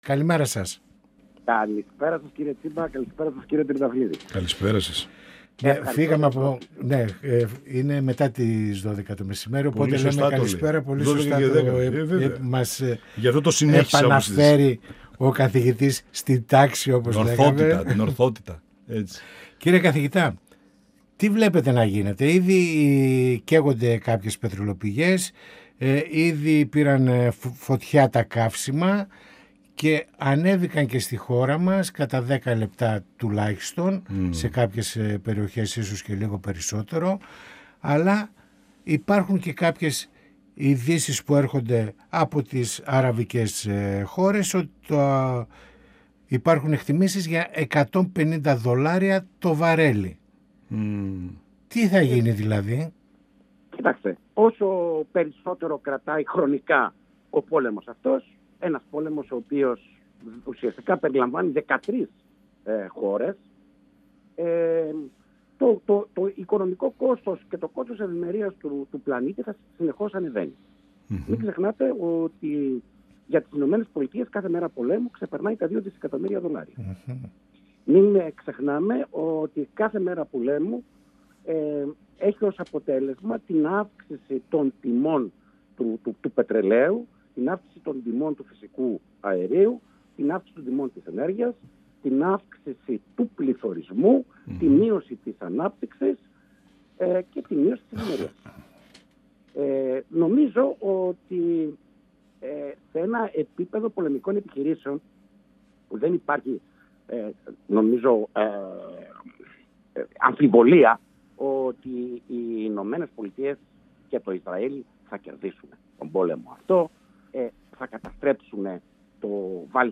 Πανοραμα Επικαιροτητας Συνεντεύξεις